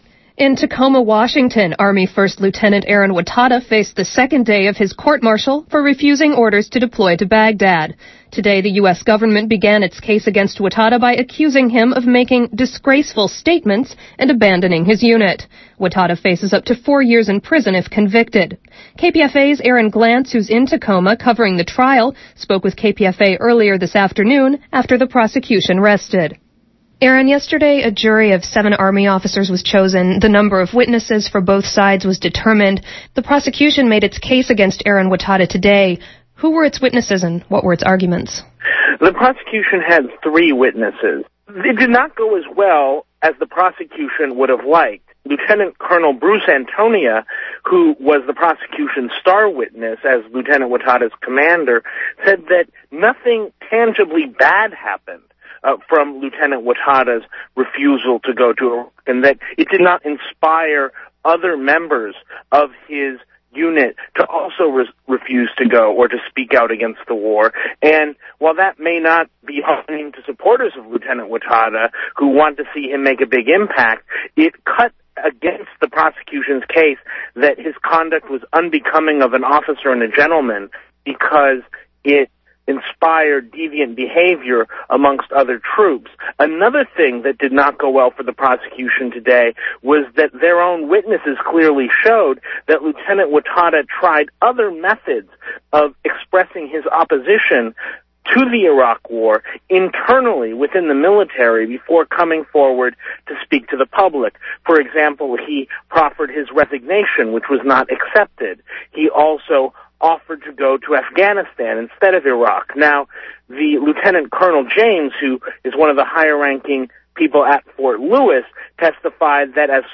Five-minute MP3 audio from KPFA Evening News of February 6, 2007.